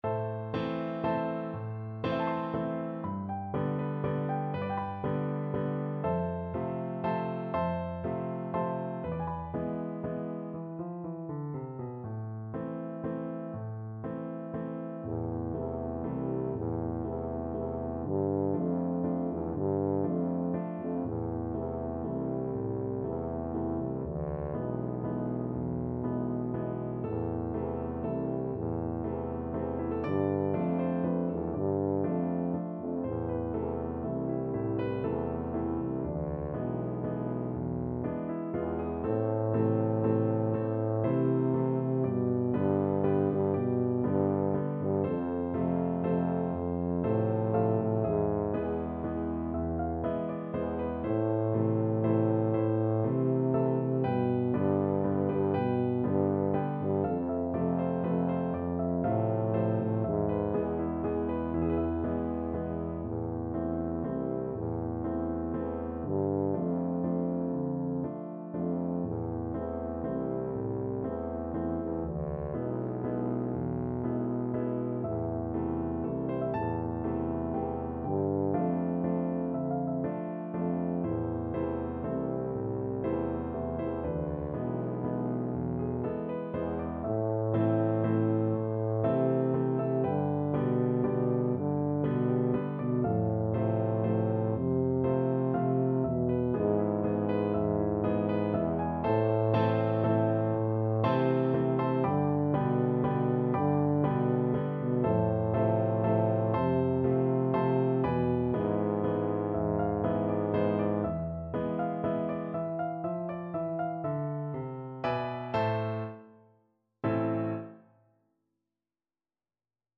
A minor (Sounding Pitch) (View more A minor Music for Tuba )
3/4 (View more 3/4 Music)
Slow Waltz .=40
B2-D4
Traditional (View more Traditional Tuba Music)